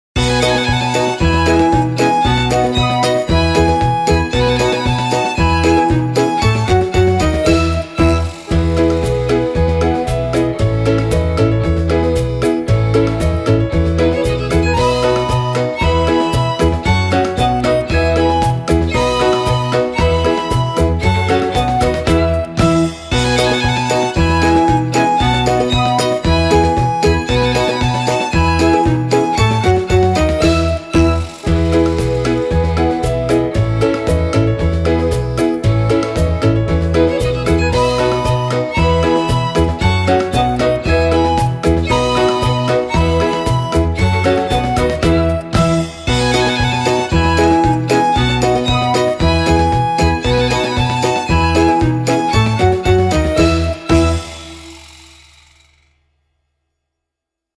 Płynie Wisła, płynie (wersja instrumentalna)
Plynie_wisla_plynie_wersja-instr.mp3